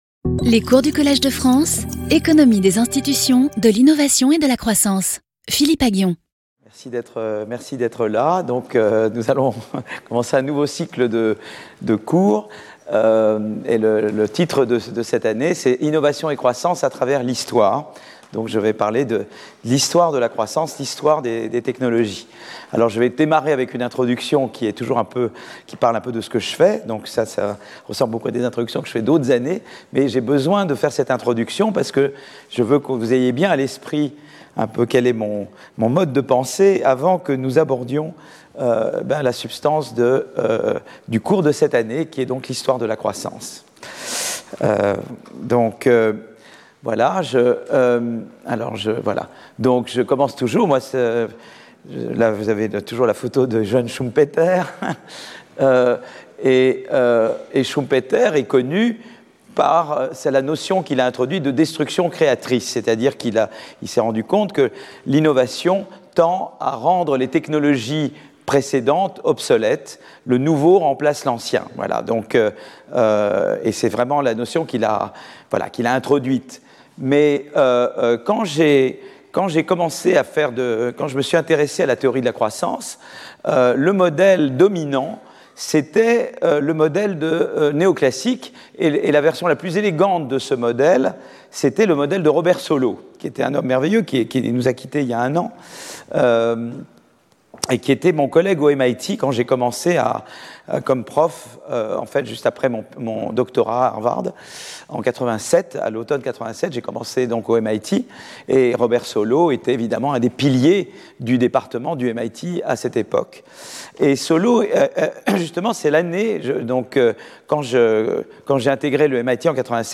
Philippe Aghion Professeur du Collège de France
Cours